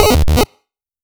sfx-atck-bite.rXYw5Gc6.wav